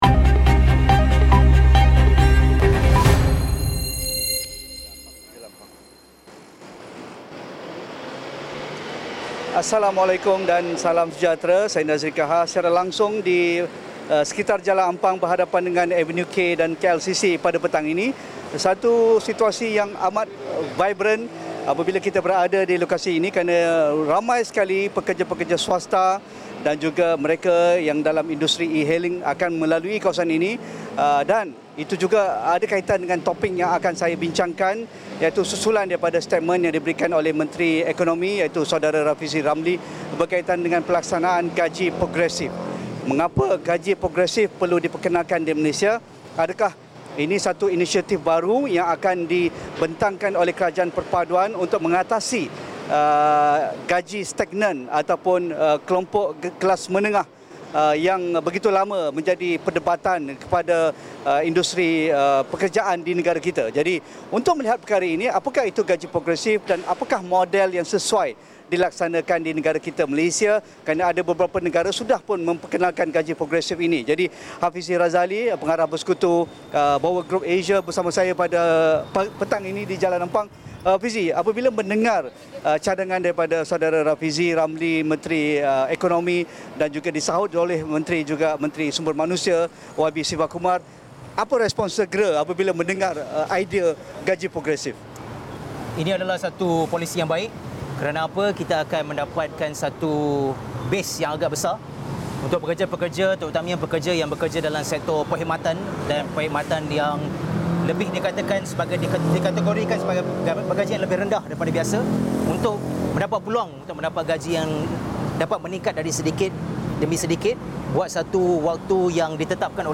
Apa model terbaik untuk melaksanakan sistem gaji progresif dan sejauh mana ia signifikan terhadap impak nilai tambah produktiviti sektor pekerjaan di Malaysia? Siaran Luar langsung dari Nasi Kandar Jamal Mohamed, Jalan Ampang Kuala Lumpur bermula 5 petang.